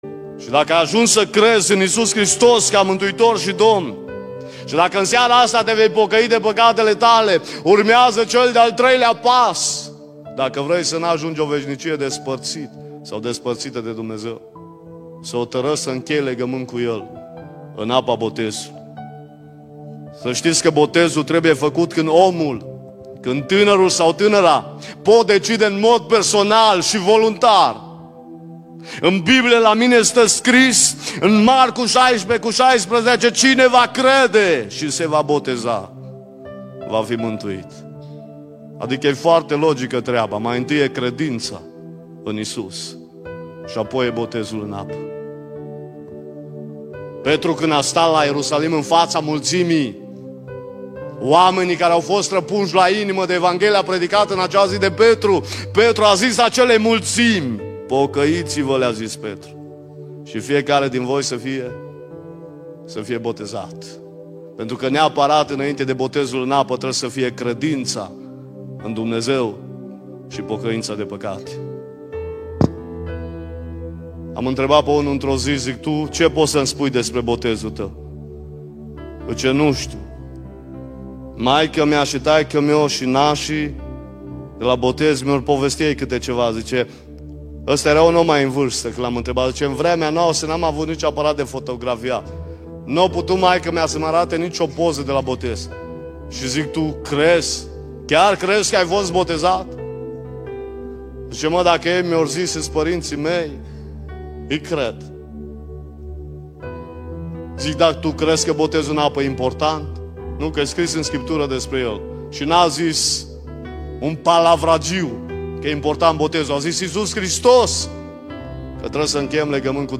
Cuprinde o selectie de predici audio si text care te ajuta sa intelegi de unde vii, cine esti si ce vrea Dumnezeu de la tine.